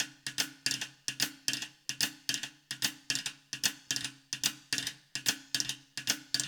POWR RIM  -R.wav